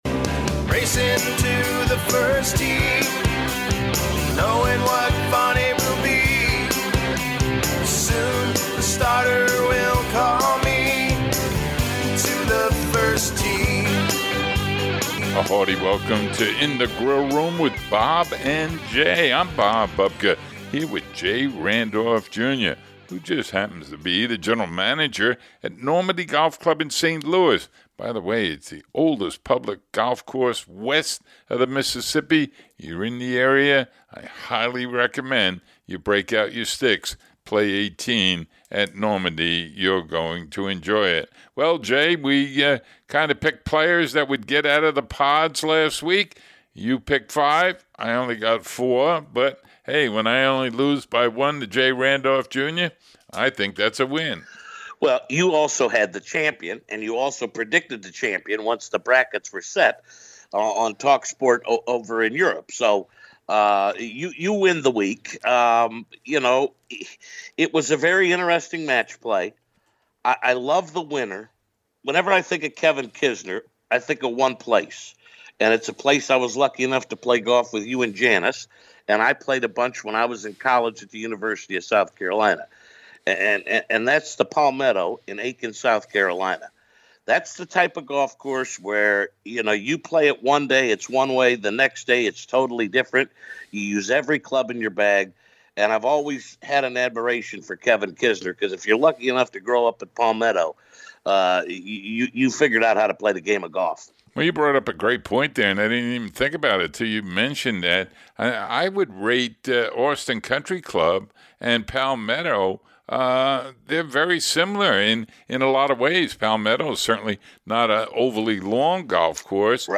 Audio from Kisner, Kuchar and Denmark's Lucas Bjerregaard who defeated Tiger followed by some Masters preview and thoughts. The podcast wraps up with a preview of the Valero Texas Open, the Augusta National Women's Championship and a Feature Interview focusing on the newly renovated Horseshoe Bay Resort in the Texas Hill Country.